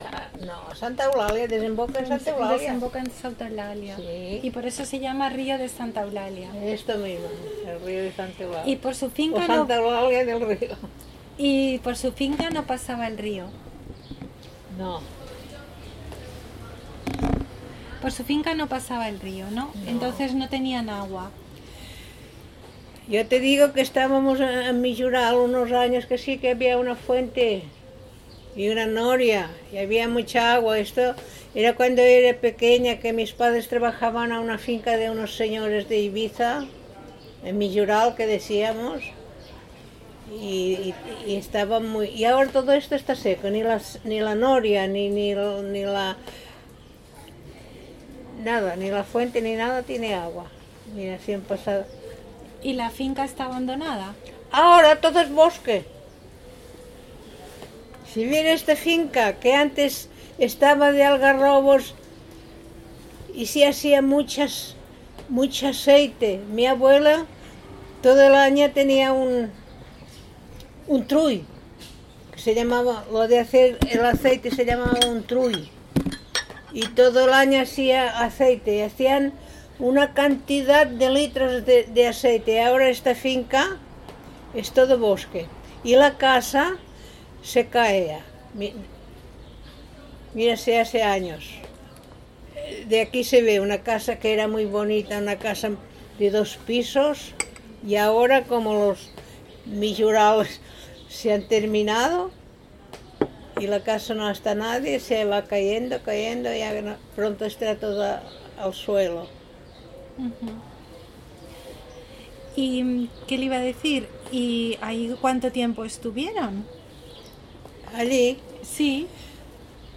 Enclave Santa Gertrudis de Fruitera (Santa Eul�ria des Riu)
Encuesta
Informantes I1:�mujer